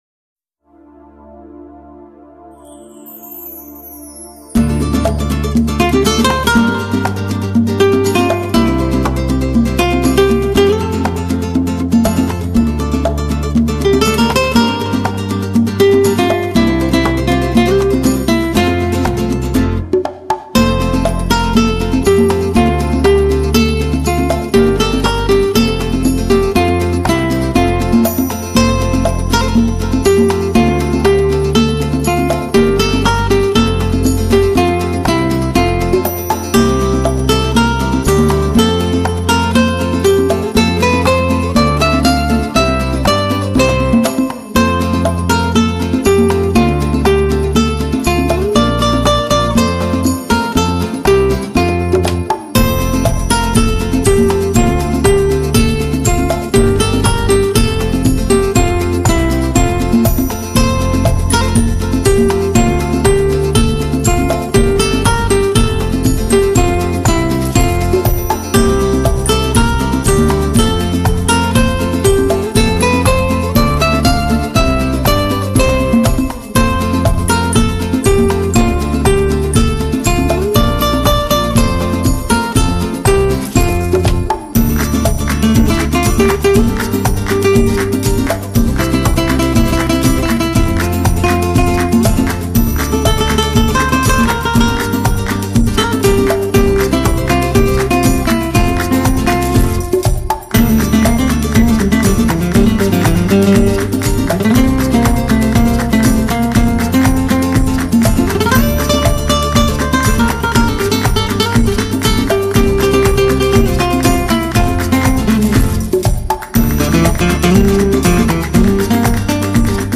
演奏版